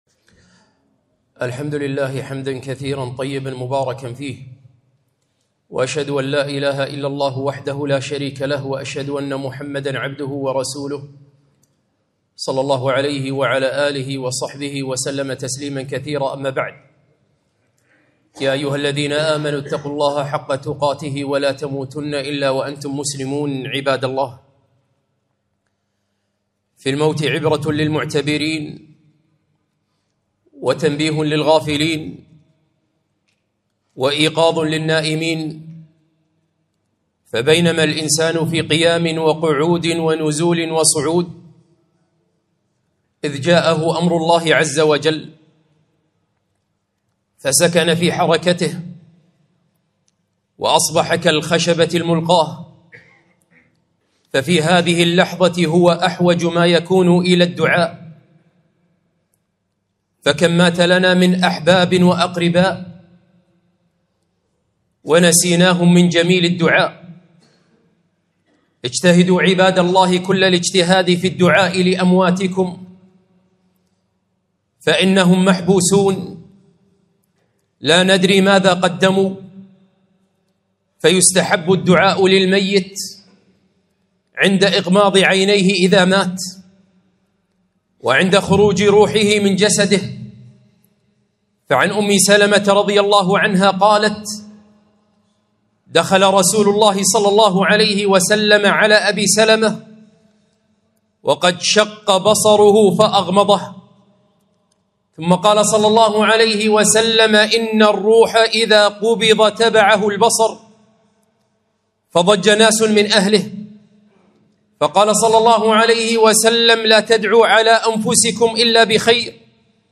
خطبة - أكرمهم بالدعاء بعد موتهم